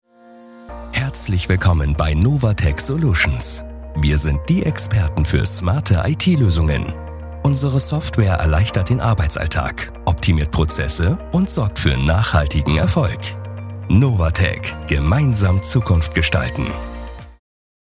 • Eigenes Tonstudio
Imagefilm
freundlich nahbar
Imagefilm-freundlich-nahbar.mp3